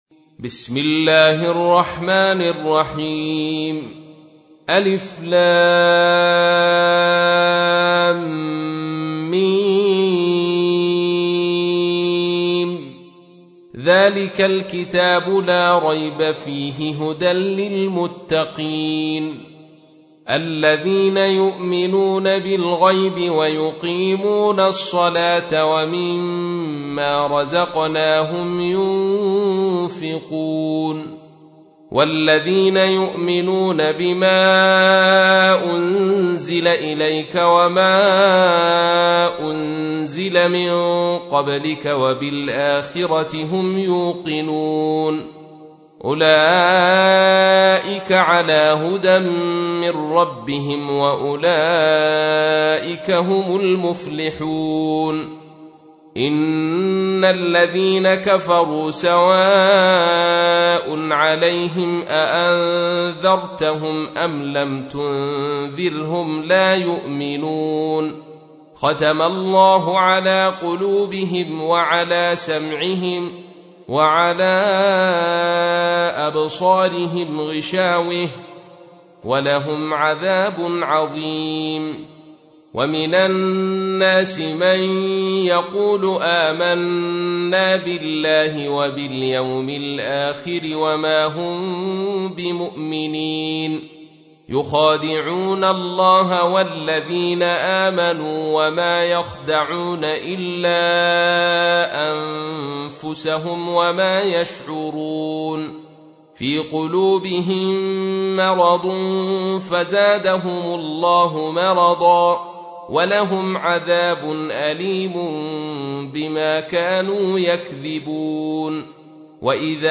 تحميل : 2. سورة البقرة / القارئ عبد الرشيد صوفي / القرآن الكريم / موقع يا حسين